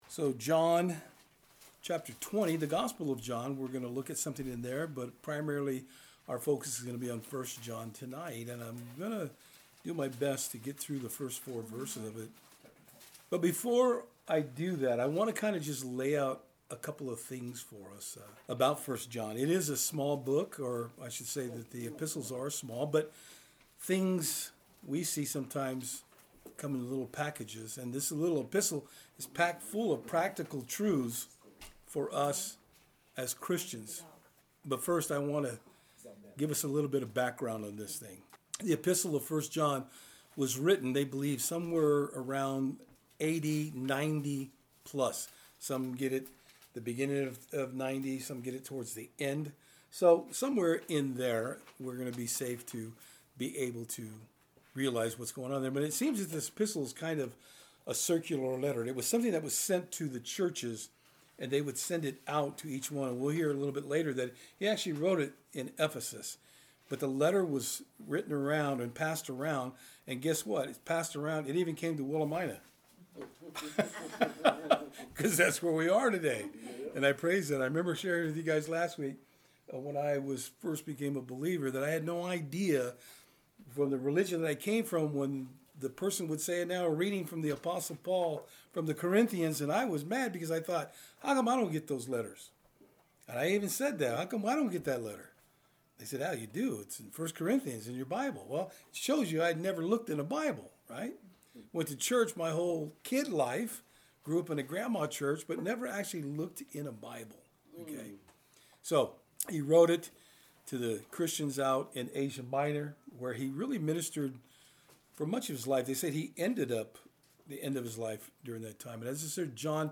1 John 1:1-4 Service Type: Thursday Eveing Studies In this second Intro to 1 John we will be looking at why the book was written.